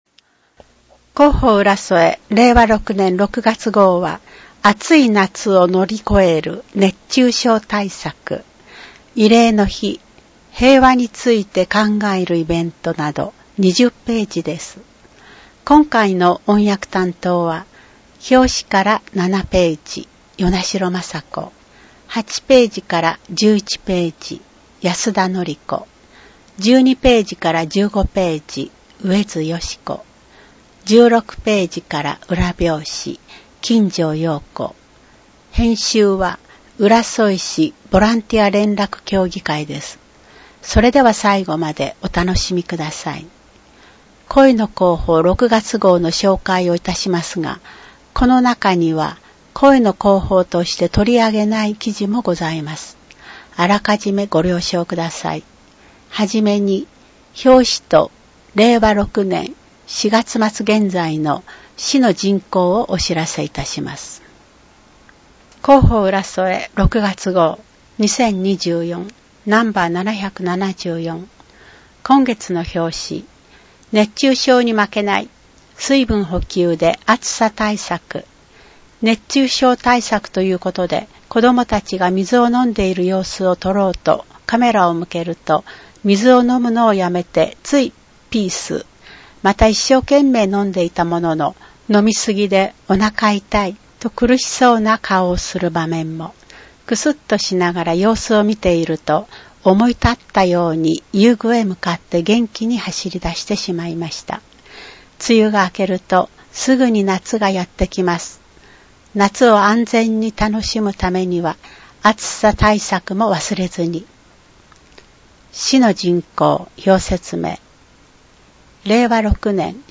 「広報うらそえ」を朗読したものを音声データ化しています。